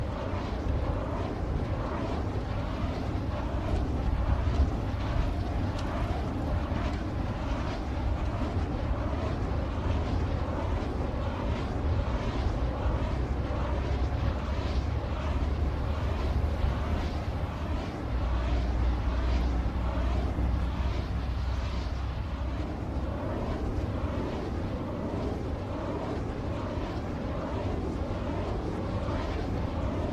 wind.mp3